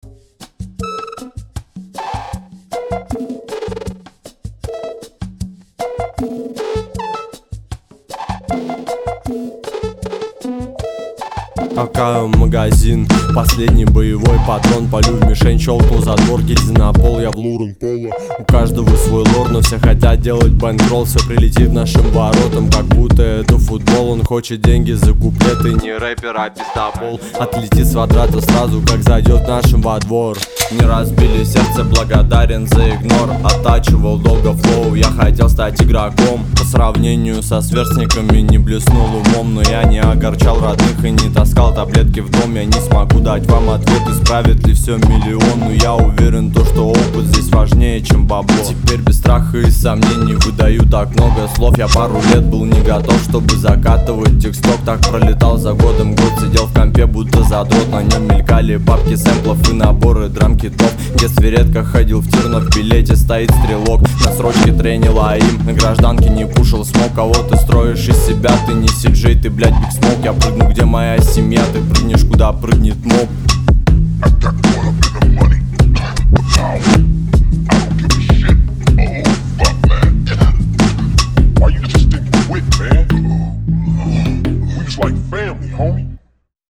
Рэп и Хип-Хоп